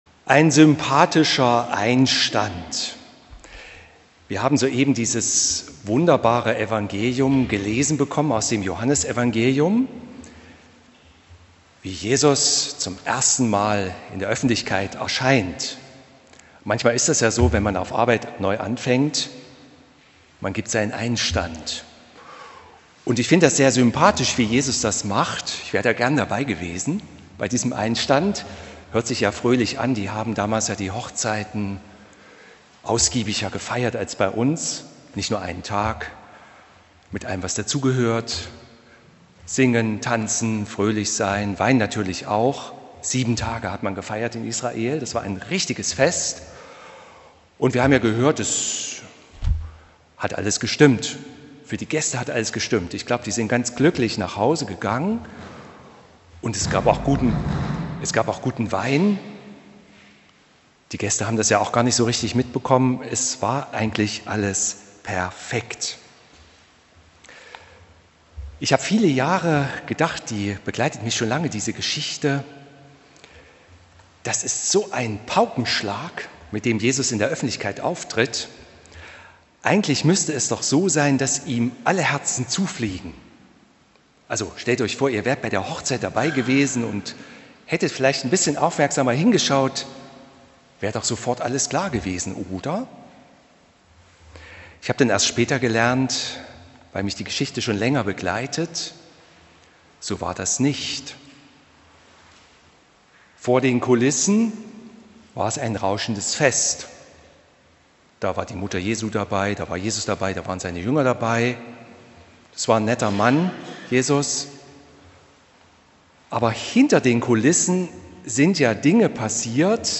Hören Sie hier die Predigt zu 2.